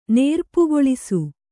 ♪ nērpugoḷisu